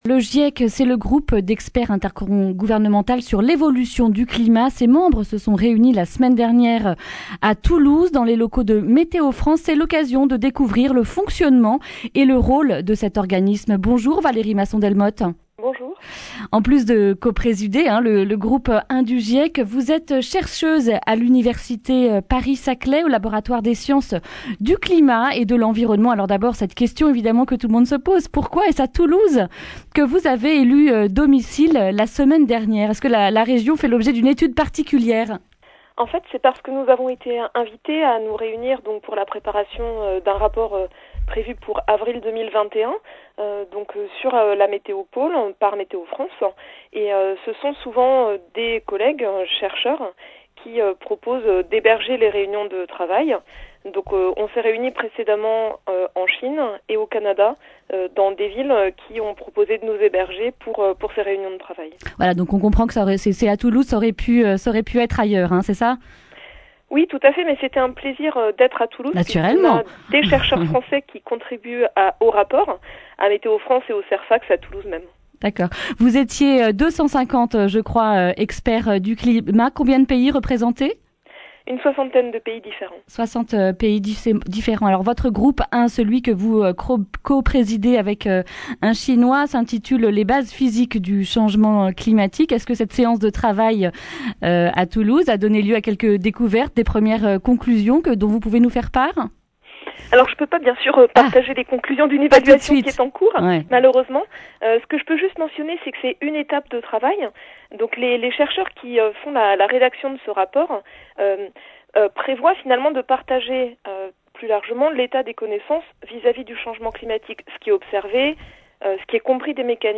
Accueil \ Emissions \ Information \ Régionale \ Le grand entretien \ Pourquoi le GIEC ?